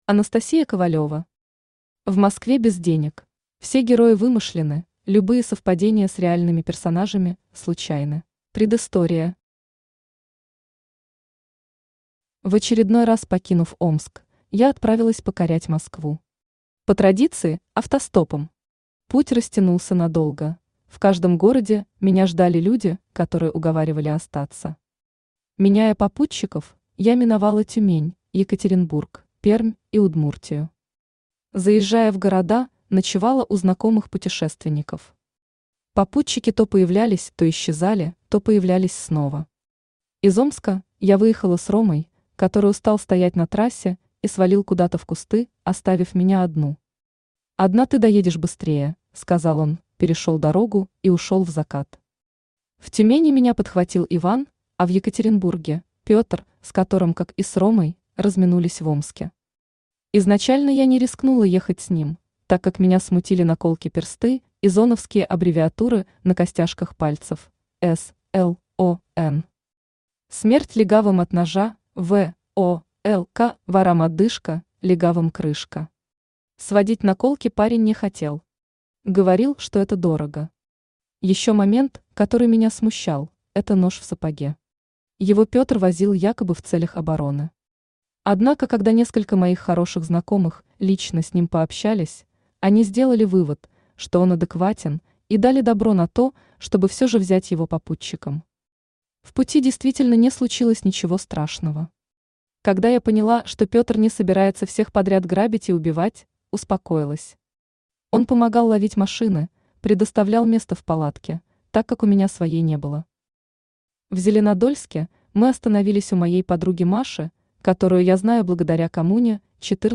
Аудиокнига В Москве без денег | Библиотека аудиокниг